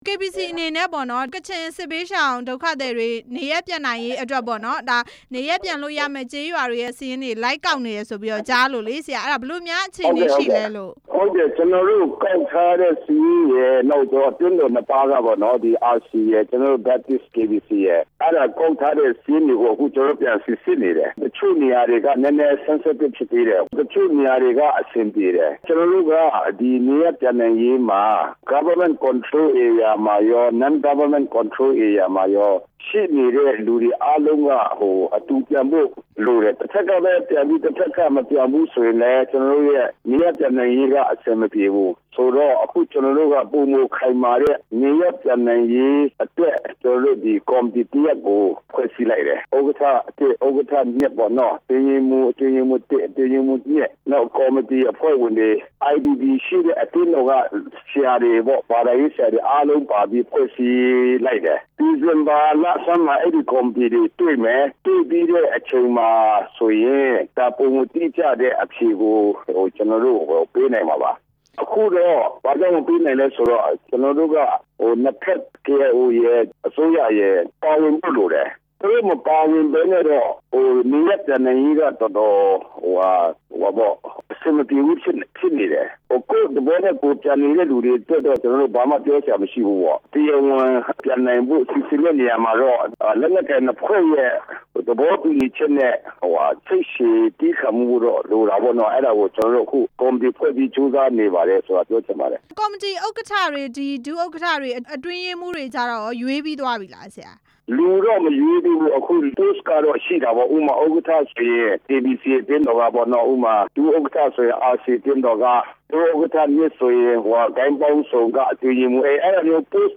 ကချင်စစ်ဘေးရှောင်များ နေရပ်ပြန်ရေးအကြောင်း မေးမြန်းချက်